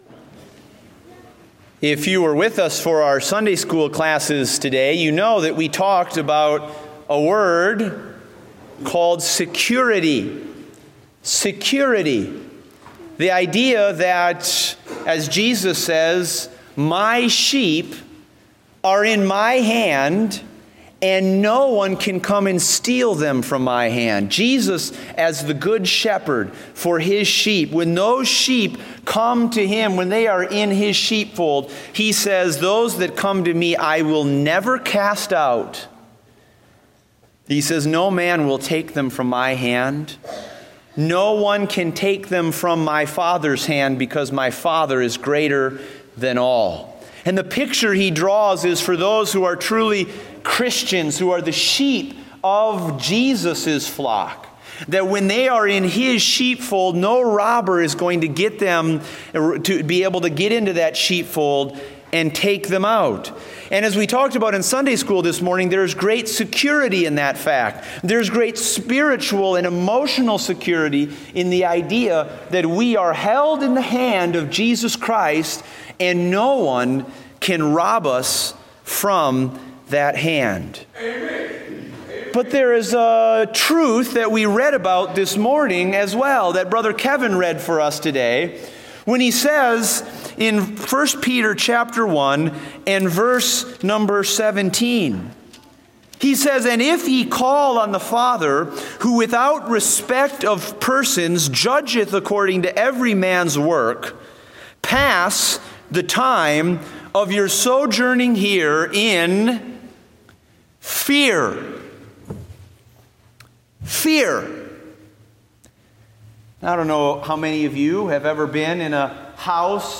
Date: November 27, 2016 (Morning Service)